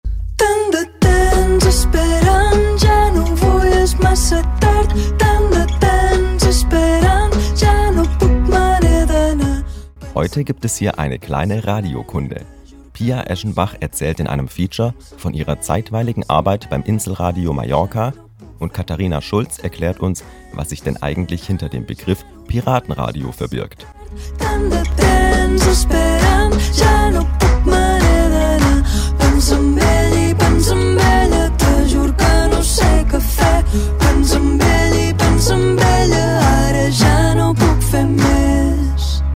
Mitarbeiterinnen und Mitarbeiter des Senders sprechen über die Anfänge des Inselradios, ihre Leidenschaft zur Insel und die Arbeit beim Radio.